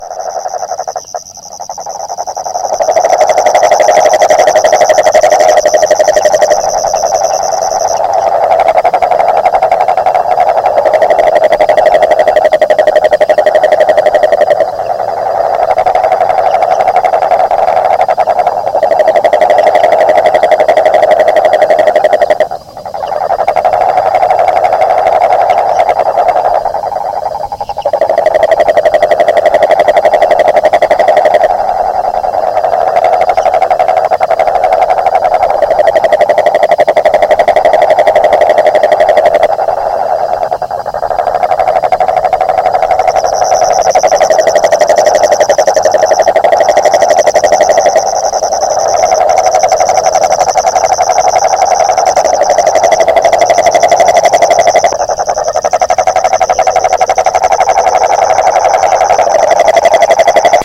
描述：夜间在池塘里对蟾蜍进行现场录音，背景是一些远处的交通噪音。